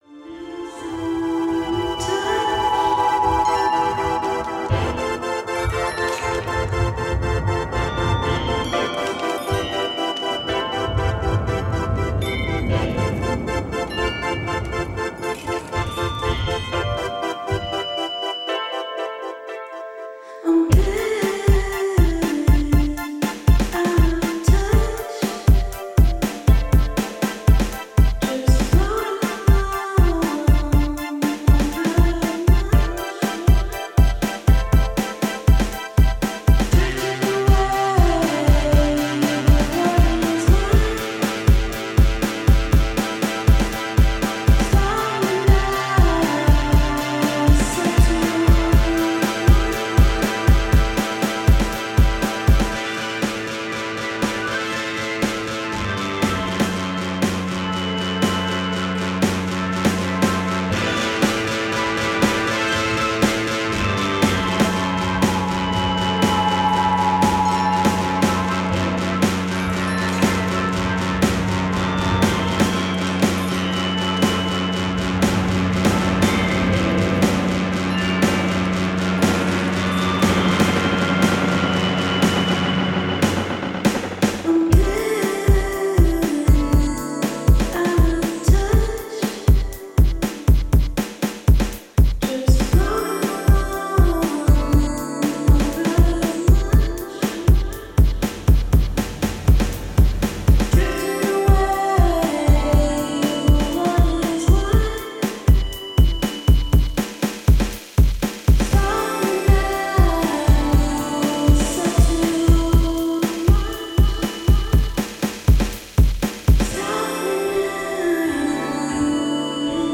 Electronic Experimental